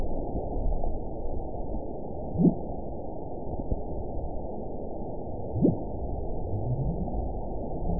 event 917107 date 03/20/23 time 15:55:59 GMT (2 years, 1 month ago) score 9.17 location TSS-AB04 detected by nrw target species NRW annotations +NRW Spectrogram: Frequency (kHz) vs. Time (s) audio not available .wav